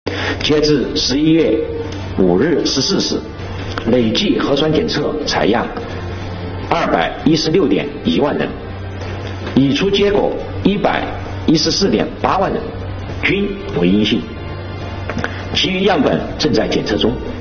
11月5日，重庆市政府新闻办举行第93场重庆市新冠肺炎疫情防控工作新闻发布会，介绍了我市疫情防控相关情况。